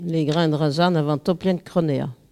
collecte de locutions vernaculaires